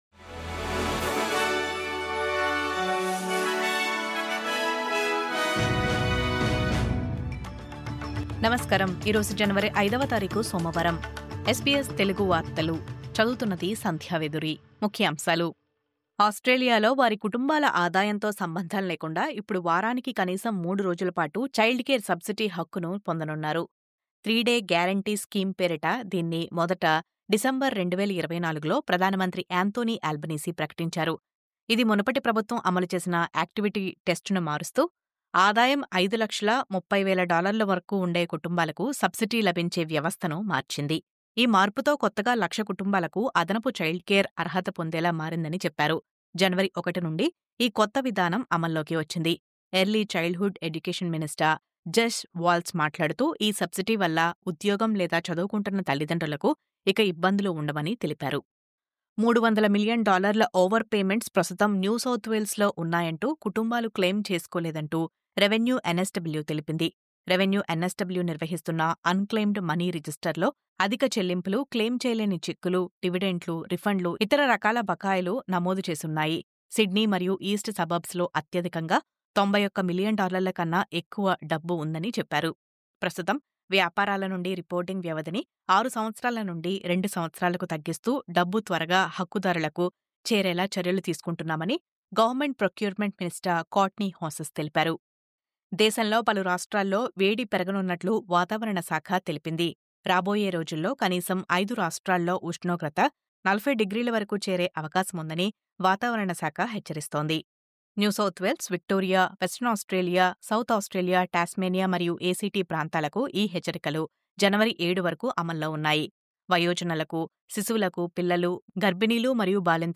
News update: 300 మిలియన్ డాలర్ల unclaimed money...క్లెయిమ్ చేసుకోవాలంటూ Revenue NSW సూచన...